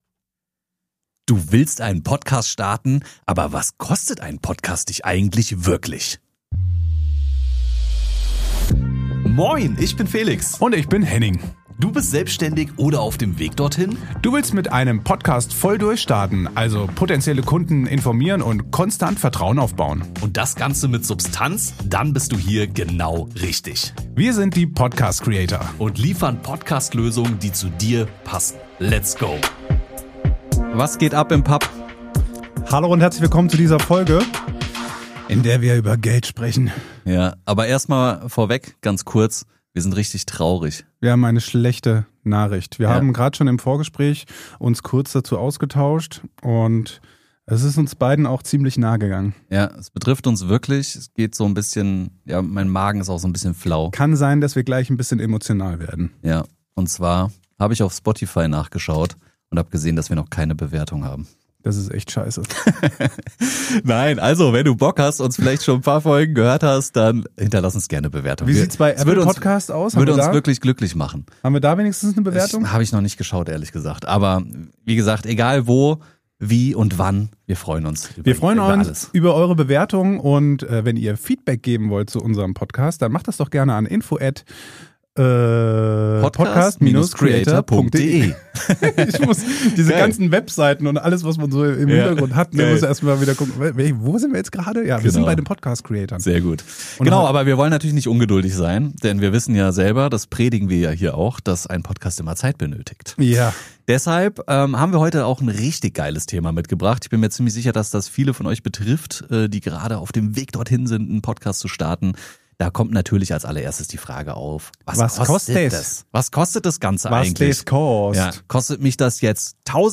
Außerdem erfährst du, warum Zeit oft der größte Kostenfaktor ist und weshalb Struktur wichtiger ist als teures Equipment. Du lernst: - wie günstig du starten kannst - welche Technik sinnvoll ist - wo Anfänger Geld verbrennen - warum Zeit der größte Kostenfaktor ist Aufgenommen in Schmitten im Taunus (nahe Frankfurt am Main) geben wir dir einen transparenten Überblick über Podcast-Kosten, Hosting, Technik, Zeitaufwand und typische Anfängerfehler.